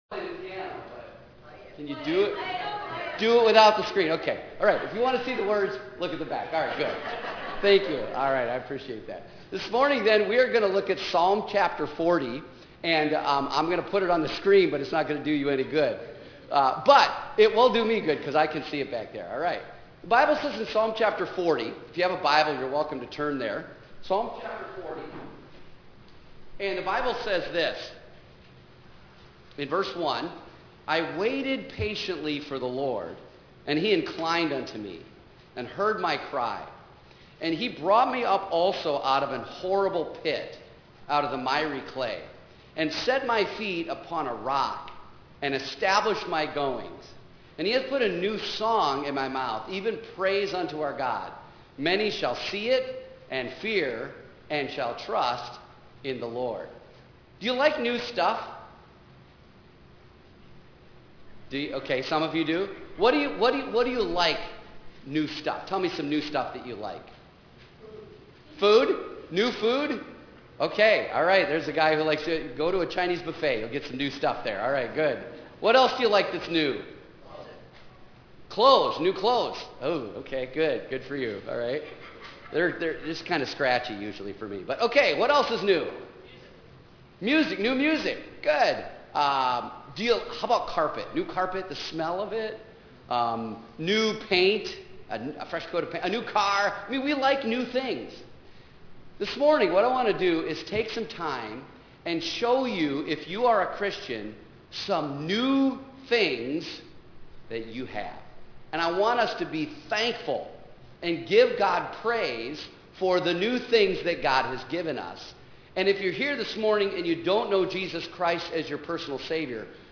concert and devotional